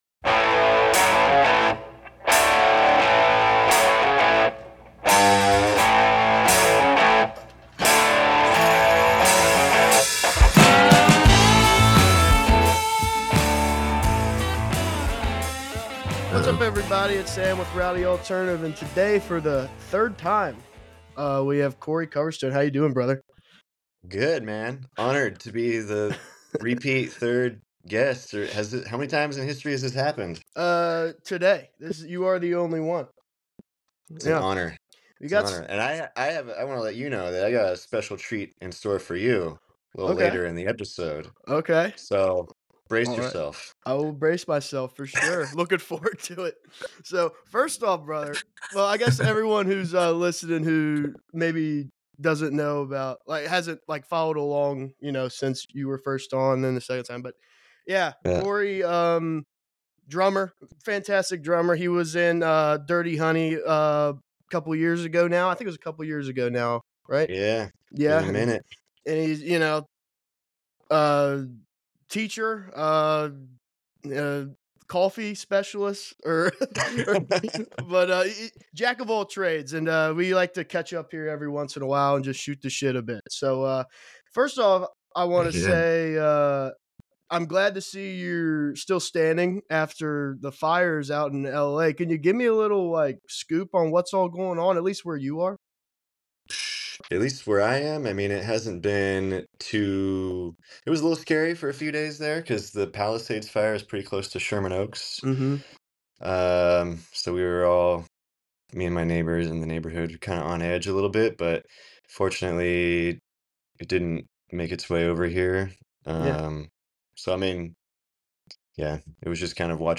Their natural chemistry makes for an insightful and laid-back discussion about music, creativity, and staying adaptable in an ever-changing industry Follow on Social Media